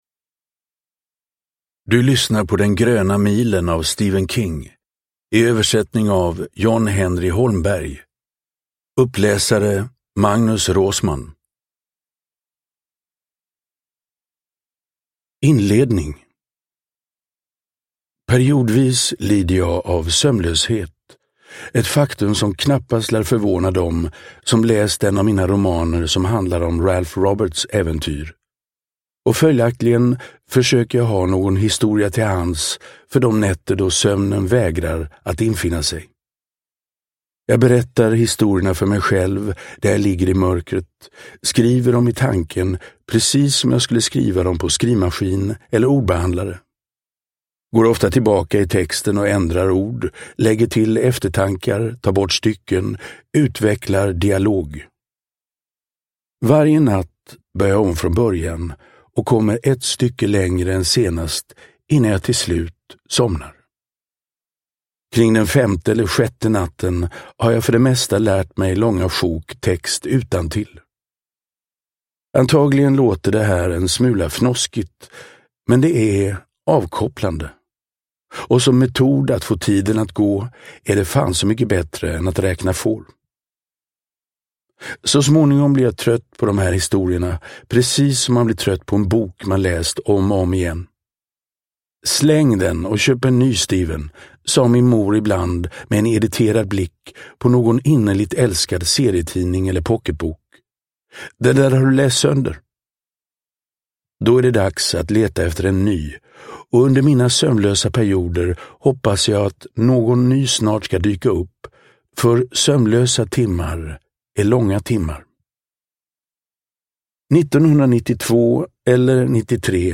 Den gröna milen – Ljudbok – Laddas ner
Uppläsare: Magnus Roosmann